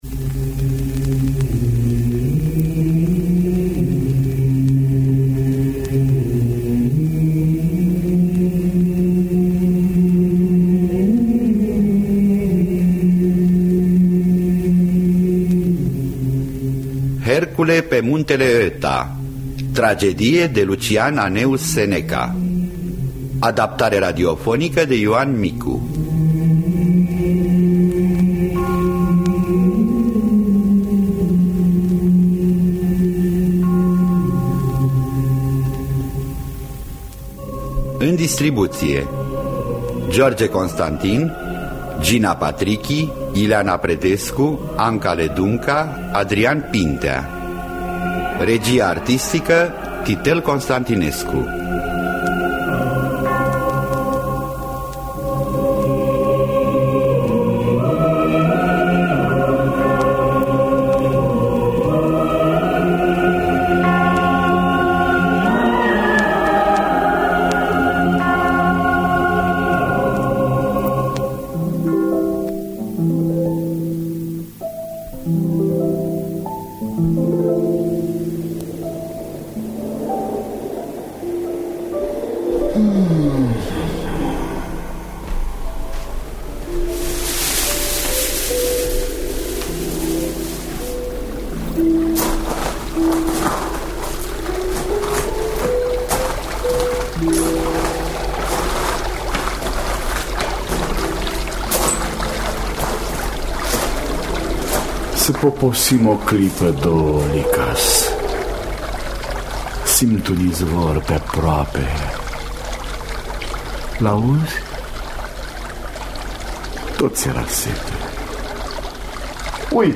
Hercule pe muntele Oeta de Seneca – Teatru Radiofonic Online